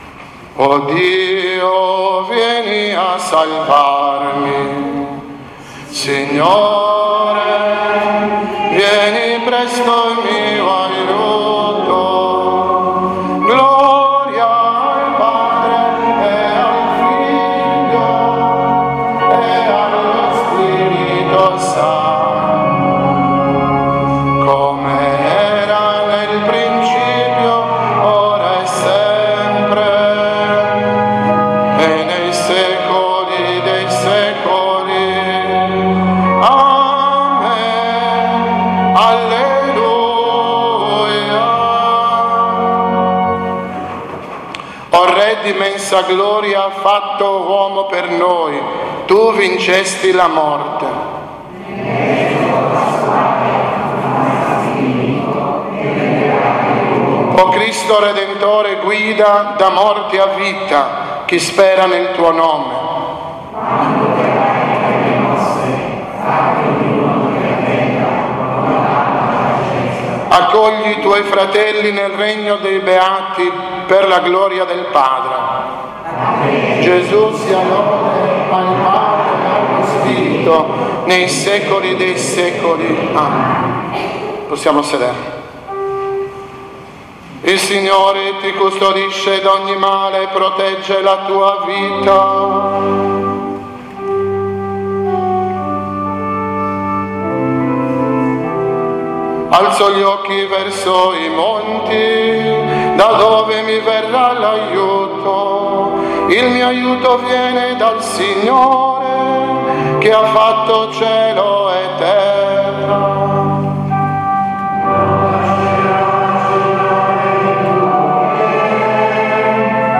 VESPRI DEI DEFUNTI
VespriDefunti.mp3